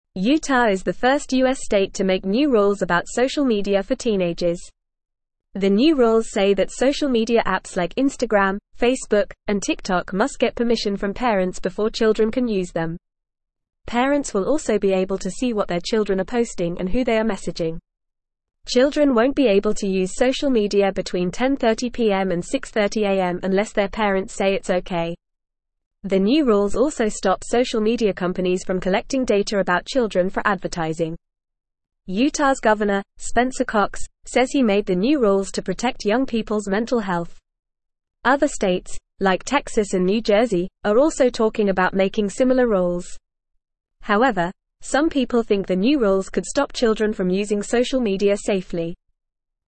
Fast
English-Newsroom-Beginner-FAST-Reading-Utah-Makes-New-Rules-for-Teen-Social-Media.mp3